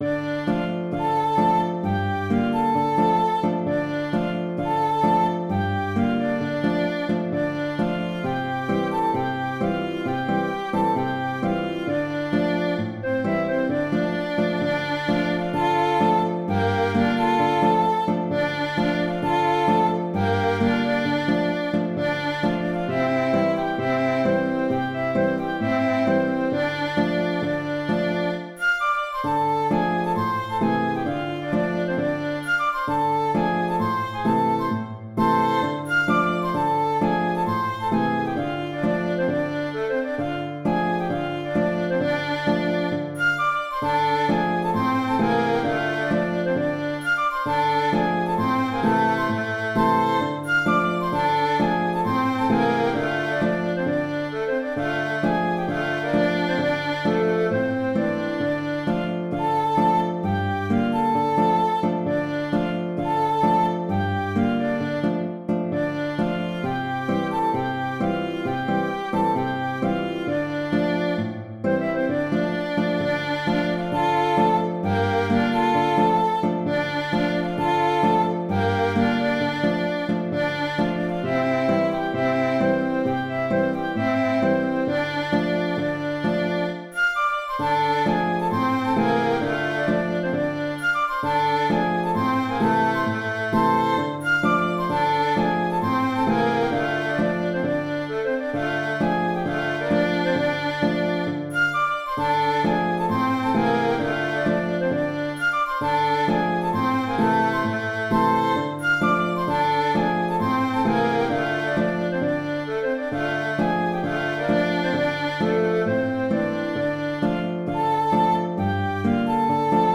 Ce morceau commence avec un thème traditionnel que je complète dans la partie B avec une composition faite à partir de la gamme blues (en D).
Il est quand même préférable de jouer le morceau avec un tempo un plus enlevé que sur le fichier mp3..